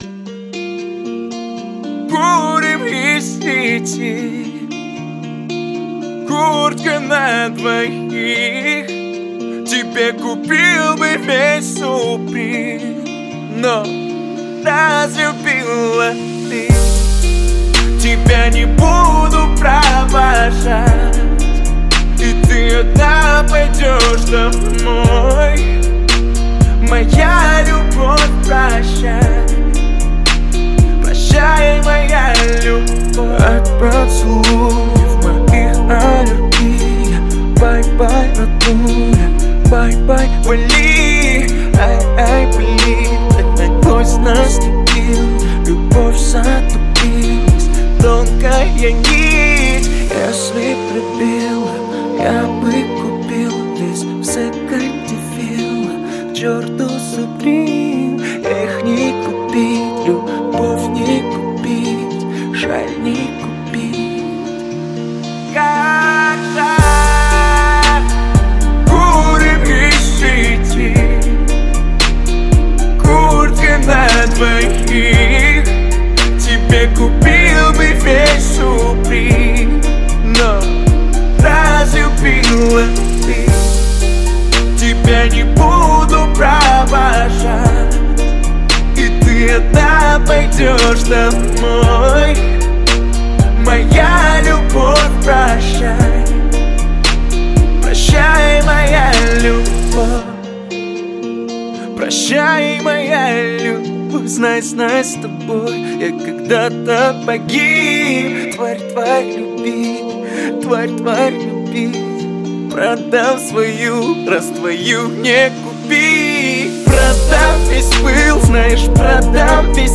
R & B
Жанр: R & B / Русский рэп